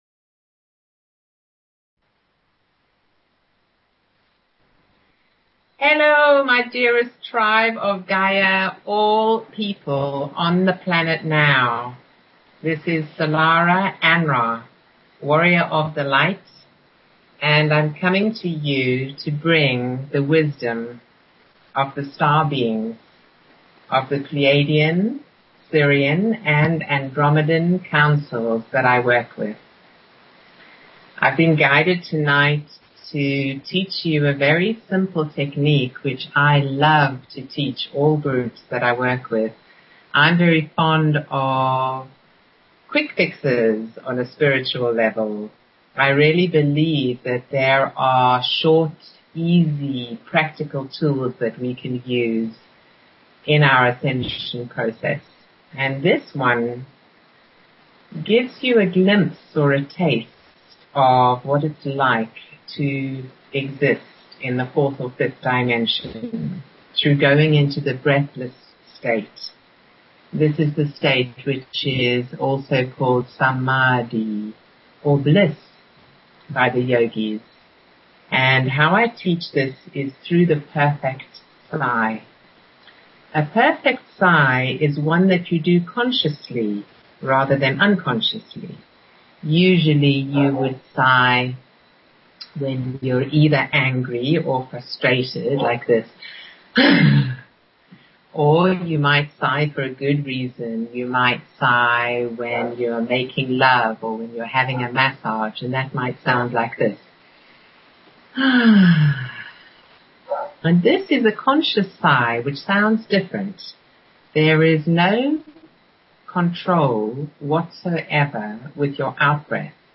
Talk Show Episode, Audio Podcast, Illumination_from_the_Councils_of_Light and Courtesy of BBS Radio on , show guests , about , categorized as